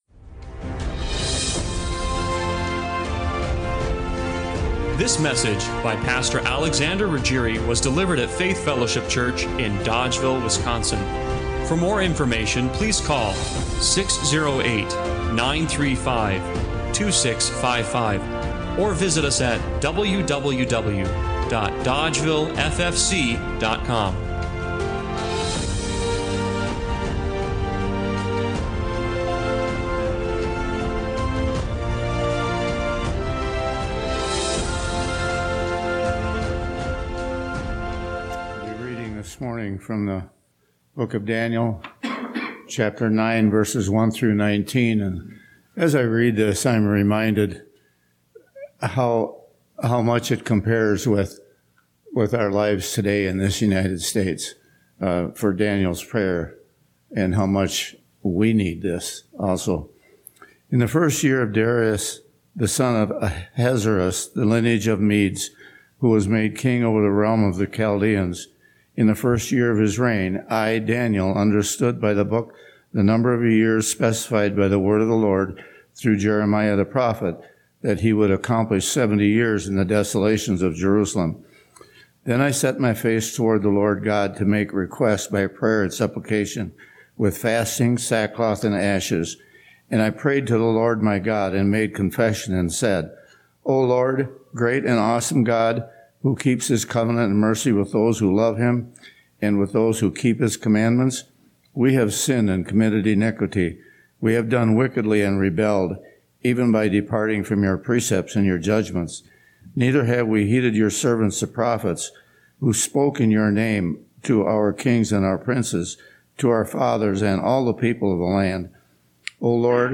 This sermon explores five key traits that made Daniel a model of devotion for us to follow.
Daniel 9:1-19 Service Type: Sunday Morning Worship What makes a person truly loyal to God?